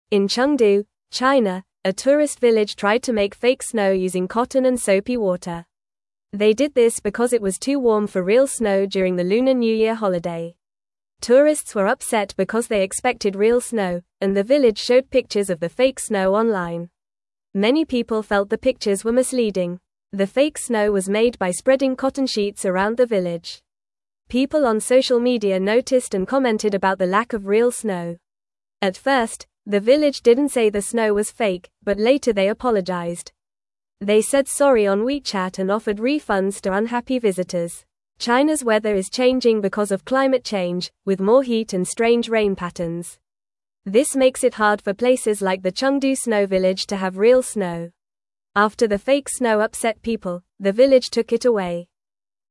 Fast
English-Newsroom-Lower-Intermediate-FAST-Reading-Village-Makes-Fake-Snow-for-Lunar-New-Year-Fun.mp3